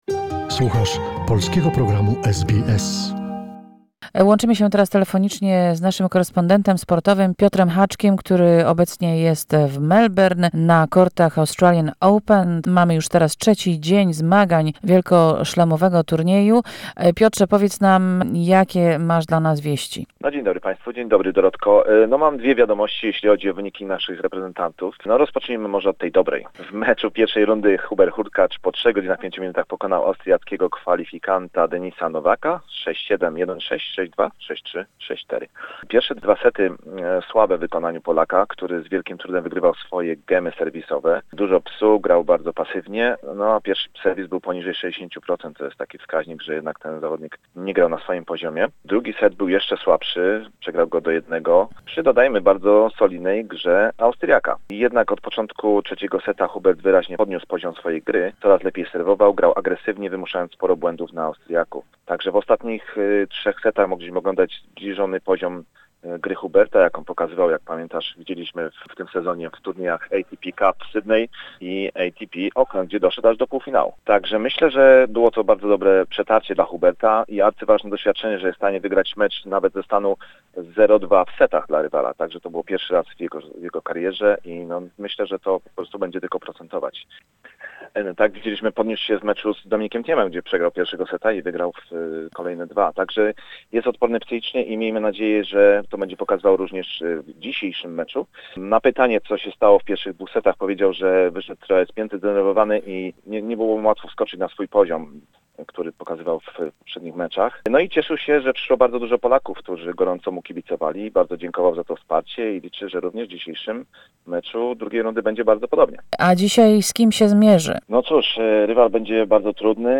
Report from the 3rd day of the Grand Slam tournament - joy for Hurkacz and Świątek, sadness for Linette